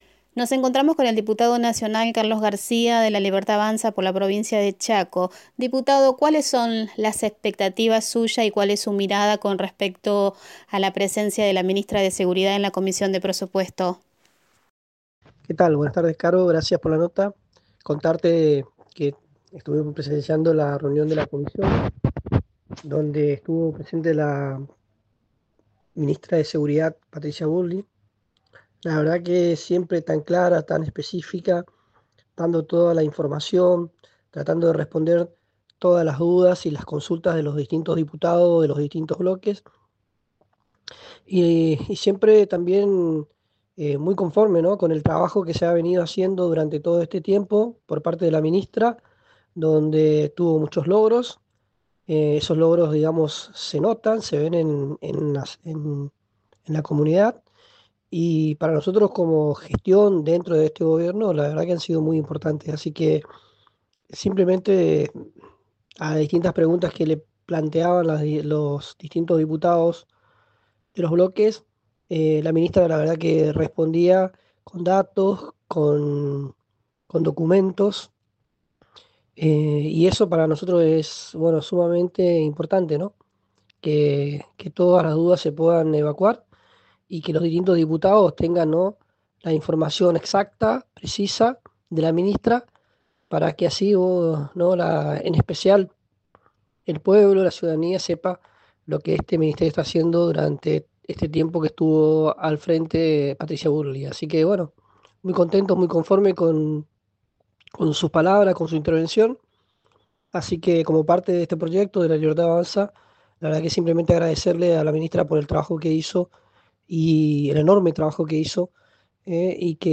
cm24horas.entrevista.dip_.garcia.mp3